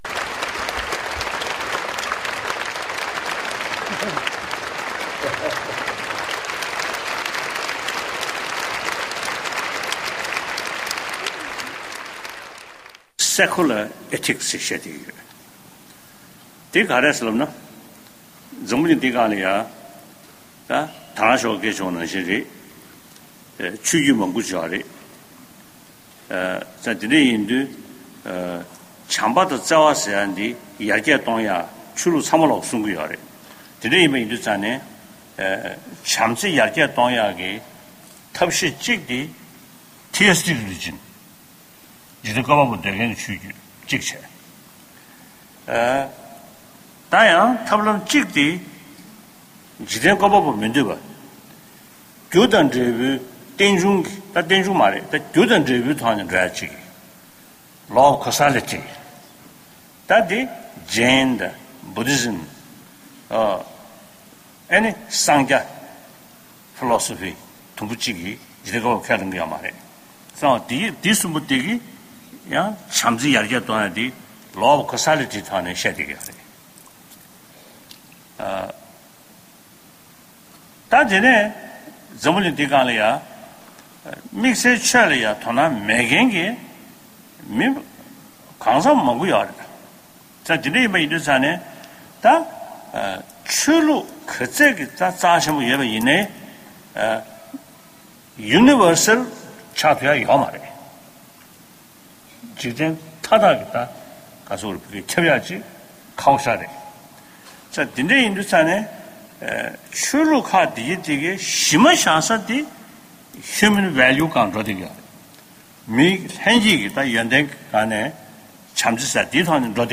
༄༅།།ཕྱི་ལོ་༢༠༡༡ཟླ་༡༠ཚེས་༣༠རེས་གཟའ་ཉི་མའི་ཉིན་༸གོང་ས་༸སྐྱབས་མགོན་ཆེན་པོ་མཆོག་གིས་ཉི་ཧོང་གི་གྲོང་ཁྱེར་ཨོ་ས་ཀའི་ནང་ཀོ་ཡ་སན་ཆེས་མཐོའི་གཙུག་ལག་སློབ་གཉེར་ཁང་བརྙེས་ནས་མི་ལོ་༡༢༥འཁོར་བའི་དུས་དྲན་མཛད་སྒོའི་ཐོག་བྱམས་པ་དང་བརྩེ་བ་ཟེར་བ་ནི་འཇིག་རྟེན་ཀུན་ལ་ཁྱབ་པ་ཞིག་ཡིན་པའི་སྐོར་བཀའ་སློབ་སྩལ་བའི་གནས་ཚུལ༎